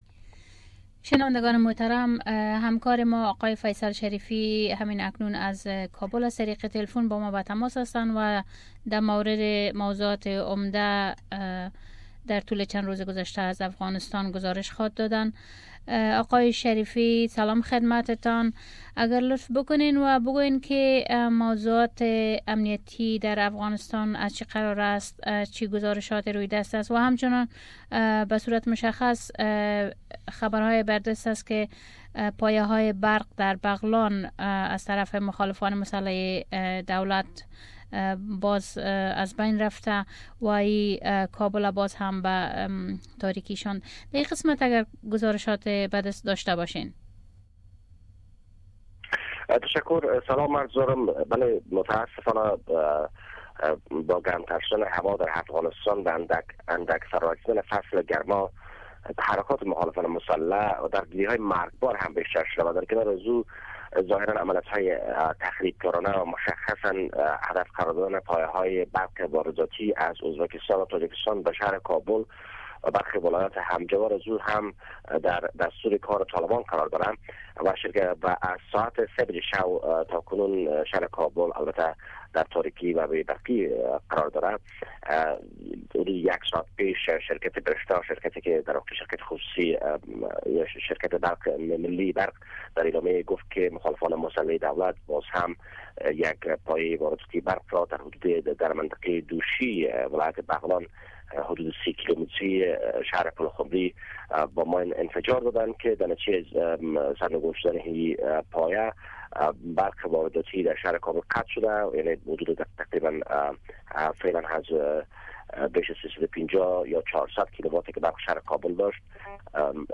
Kabul Report
Report From Kabul Source